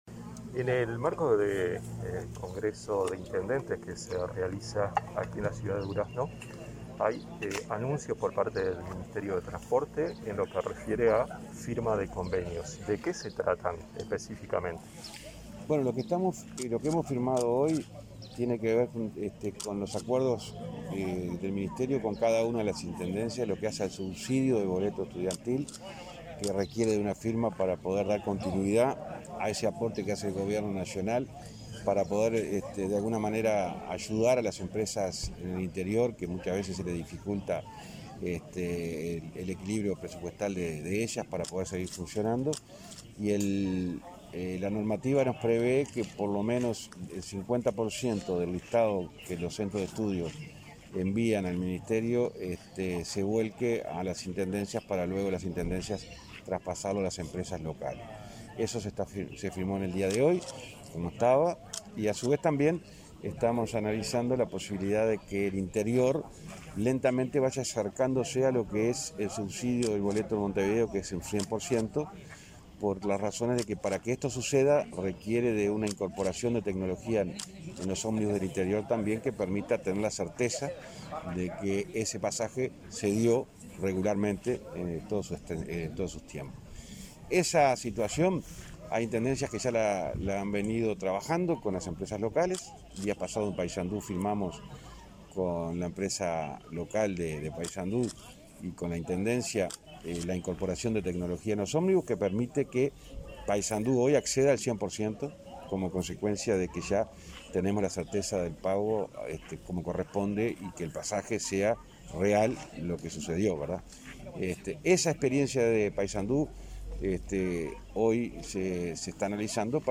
Declaraciones del ministro de Transporte, José Luis Falero
En el marco del Congreso de Intendentes, que se realiza este jueves 21 en Durazno, el ministro de Transporte, José Luis Falero, firmó convenios con